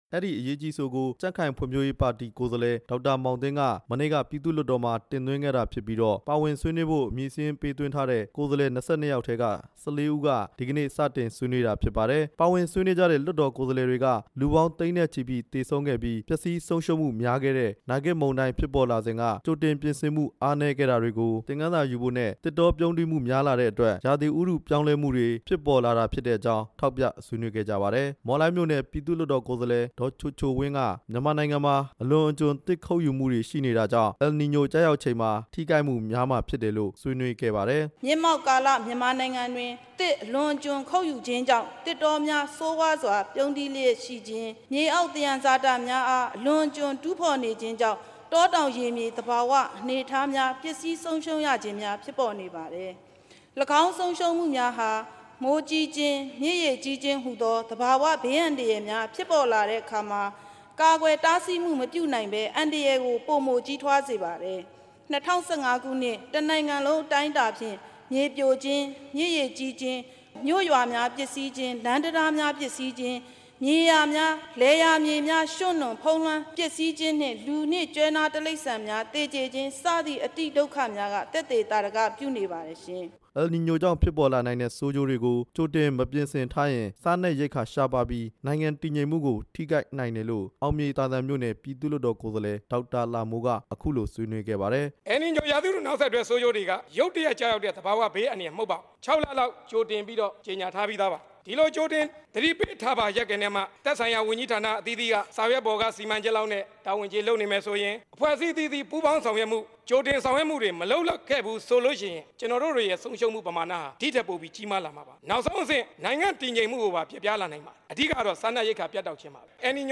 မြန်မာနိုင်ငံမှာ ရာသီဥတုပြောင်းလဲမှုနဲ့ အယ်လ်နီညို အကျိုးသက်ရောက်မှုကြောင့် ဖြစ်ပေါ်လာနိုင်တဲ့ သဘာဝဘေးအန္တရာယ် ကြိုတင်ကာကွယ်ရေးလုပ်ငန်းတွေကို အစိုးရဌာနဆိုင်ရာအဆင့်ဆင့် ပူးပေါင်းဆောင်ရွက်ကြဖို့ အစိုးရထံ တိုက်တွန်းကြောင်း အရေးကြီးအဆိုကို တပ်မတော်ကိုယ်စားလှယ် ၂ ဦးအပါအဝင် ကိုယ်စားလှယ် ၁၄ ဦးက ဒီနေ့ ပြည်သူ့လွှတ်တော်မှာ ထောက်ခံဆွေးနွေးကြပါတယ်။